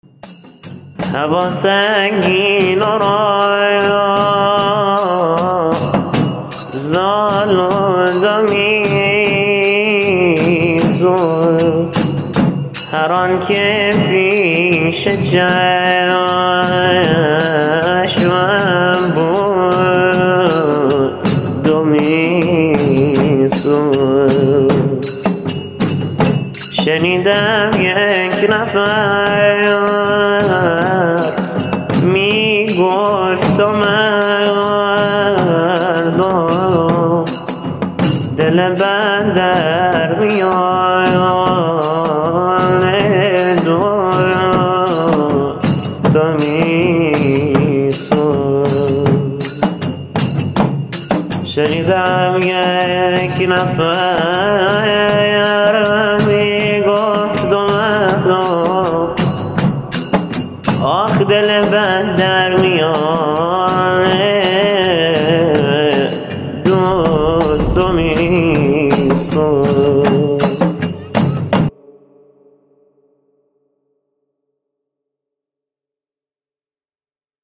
چاووشی خوانی | دل بندر میان دود می سوخت